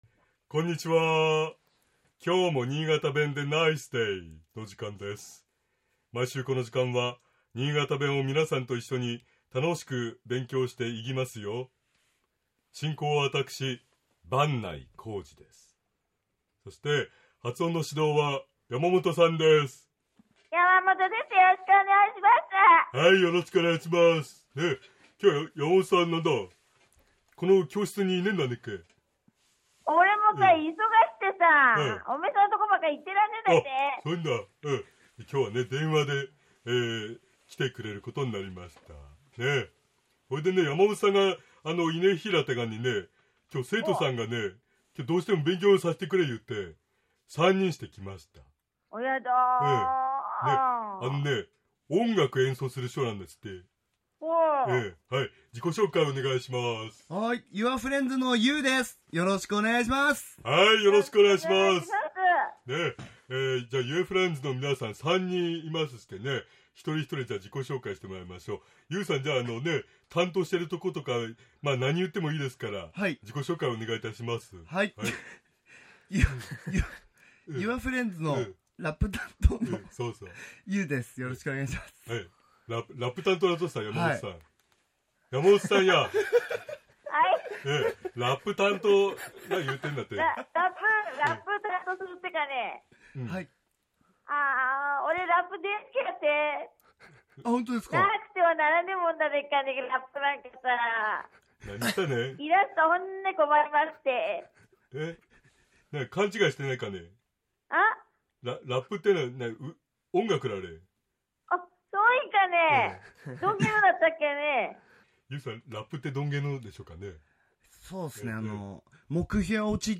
今日は、御馳走に関する言葉について勉強しましょう。 まず、新潟弁では、「御馳走」の事を「ごっつぉ」と発音します。